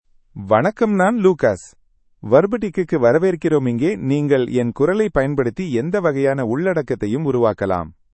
Lucas — Male Tamil AI voice
Lucas is a male AI voice for Tamil (India).
Voice sample
Male
Lucas delivers clear pronunciation with authentic India Tamil intonation, making your content sound professionally produced.